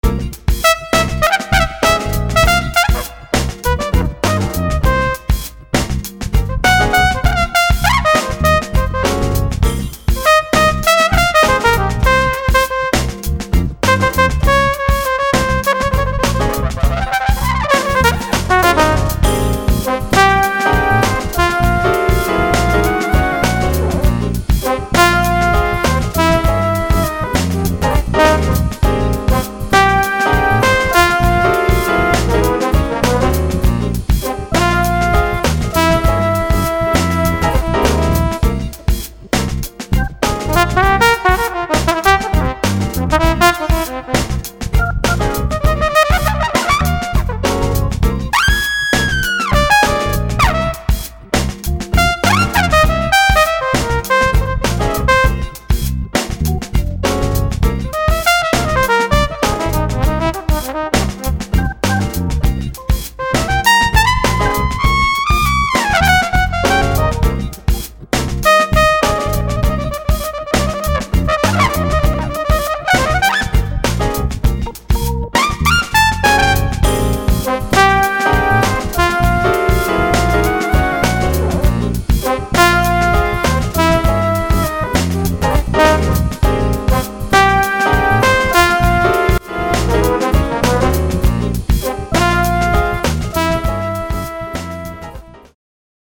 Les instruments visés sont la trompette, le trombone, la flûte, la clarinette et le saxophone.
Seuls, les modèles physiques permettent de simuler les variations de timbre de ces instruments en fonction des paramètres de jeu (souffle notamment) et les différents types d'articulation entre notes.
interprétés au clavier